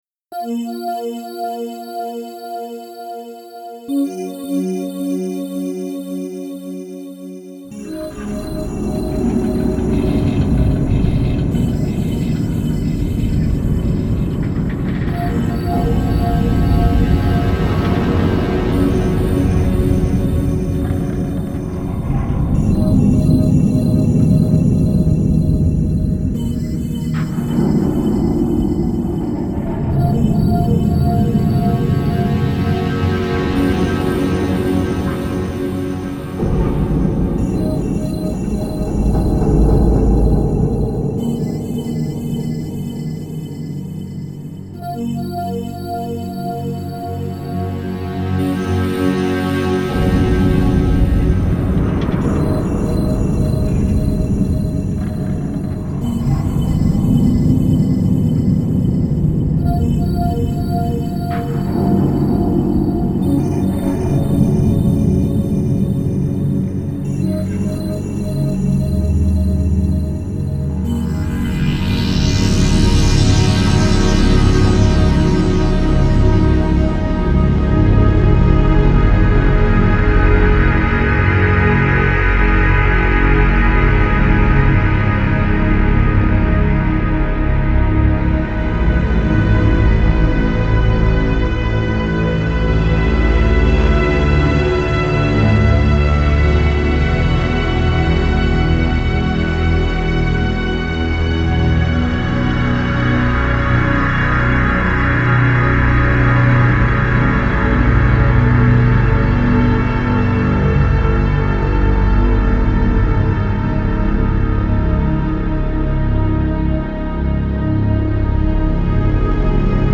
Genre: Dark Ambient.